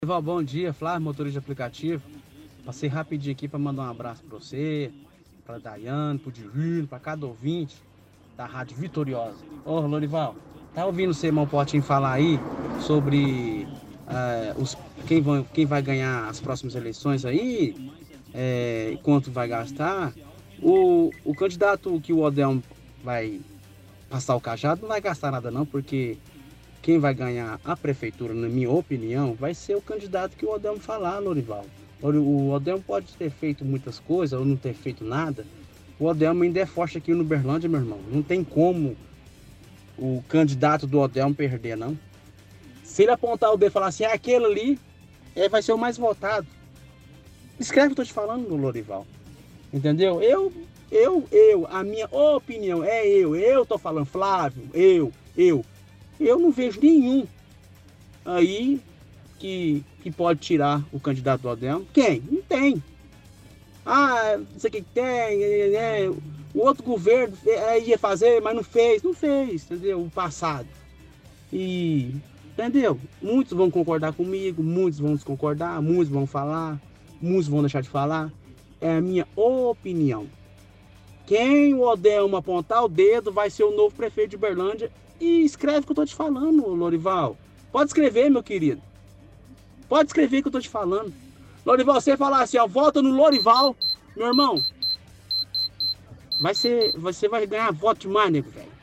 – Ouvinte comenta que o candidato que vai suceder o prefeito Odelmo, se for indicado pelo prefeito, vai ganhar, qualquer um que seja.